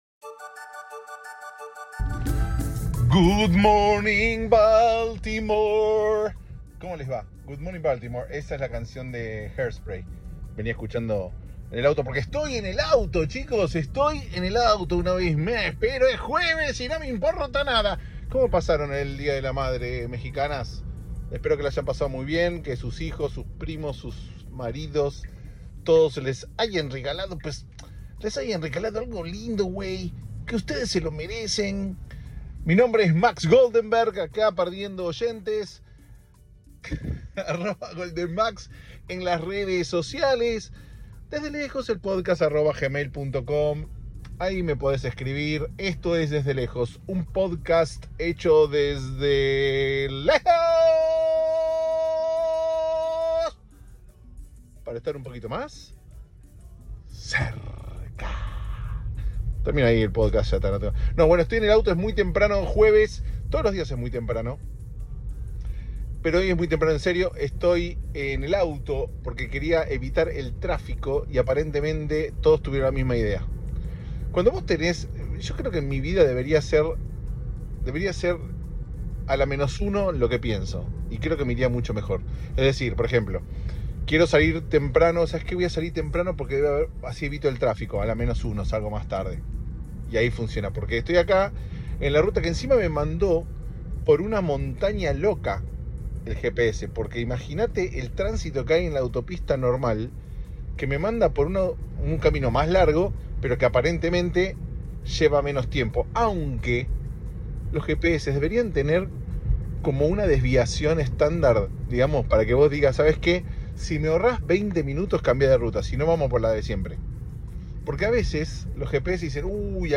Un episodio de esos de la ruta. Cuando vas manejando y, de pronto, se prende el REC y acá estamos.